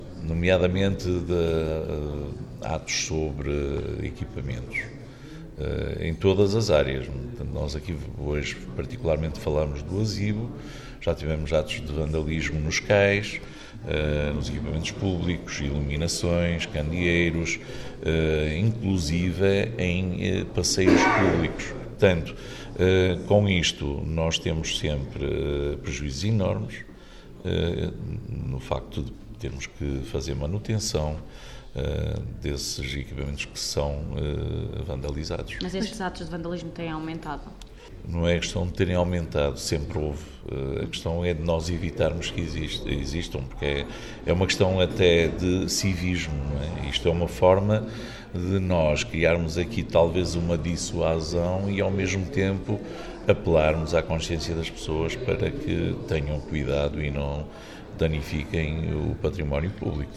O autarca ainda acrescentou que esta poderá ser uma forma de dissuasão para apelar à consciência da população para não danificar o património público: